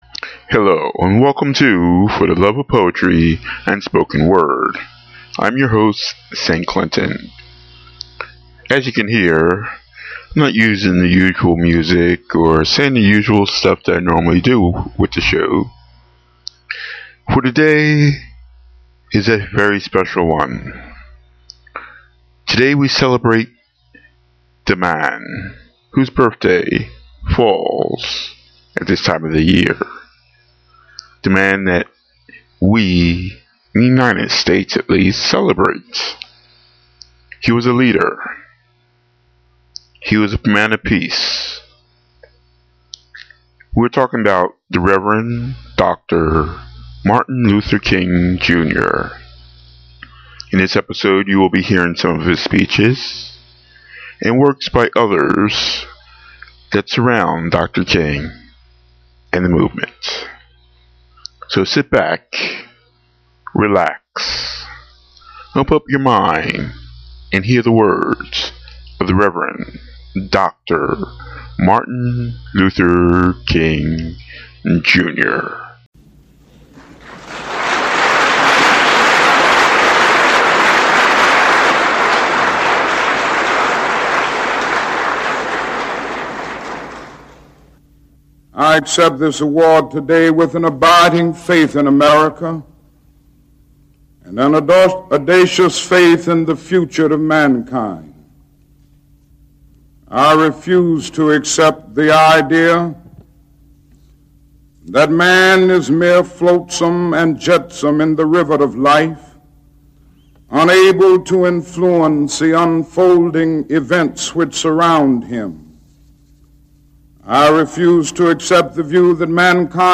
Speeches, poetry and more